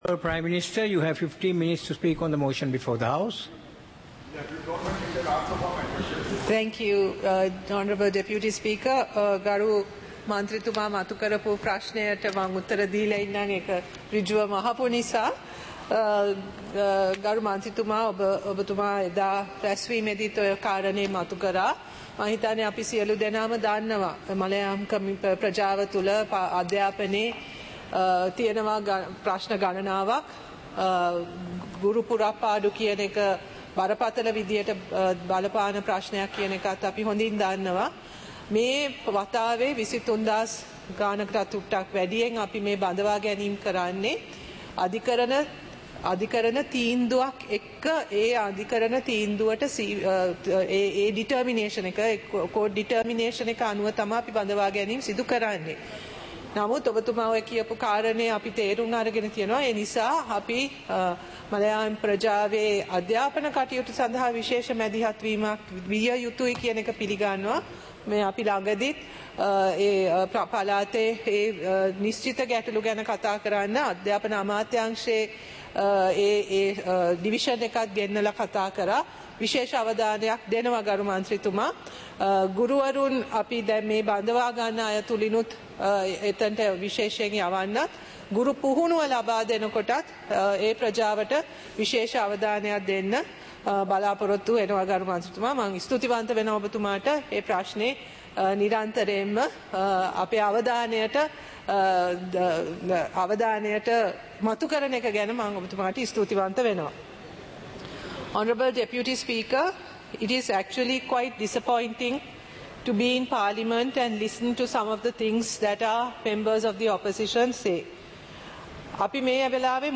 சபை நடவடிக்கைமுறை (2026-03-19)